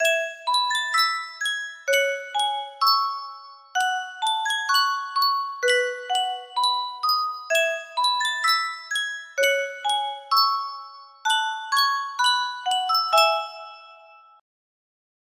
Yunsheng Music Box - In the Bleak Midwinter 6069 music box melody
Full range 60